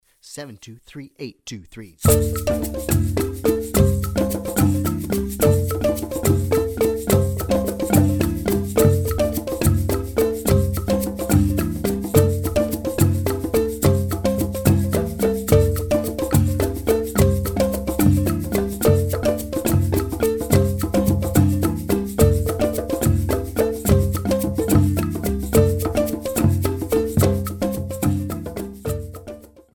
Very Fast Triple Meter - 215 bpm